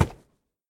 horse_wood2.ogg